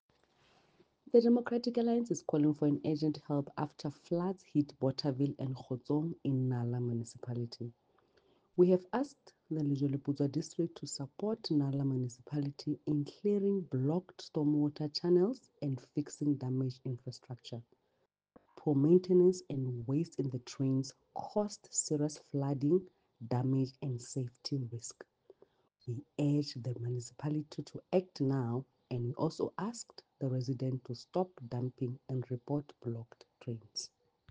English and Sesotho soundbites by Cllr Mahalia Kose and